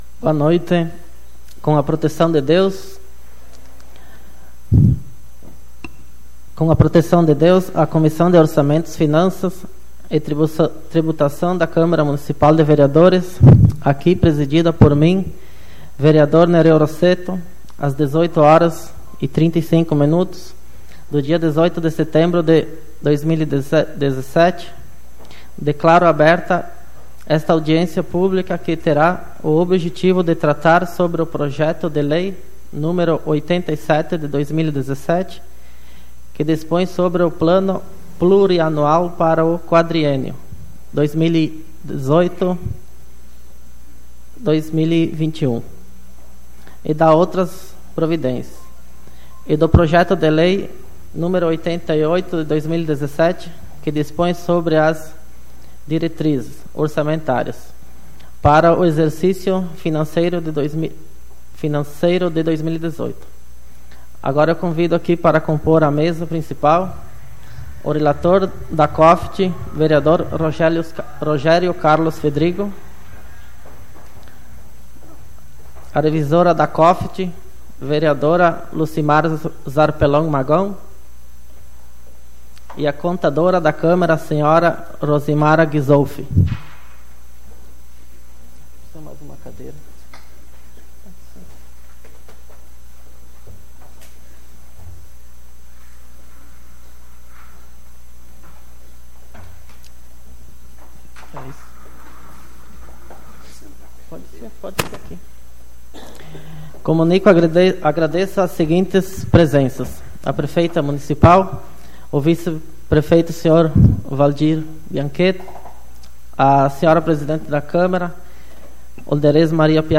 Audiência Pública de 18/09/2017